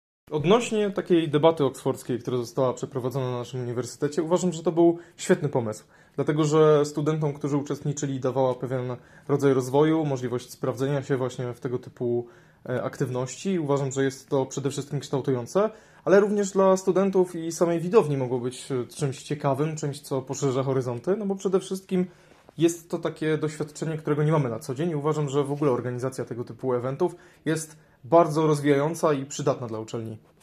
Mieliśmy przyjemność porozmawiać z jednym ze studentów, który słuchał razem z nami debaty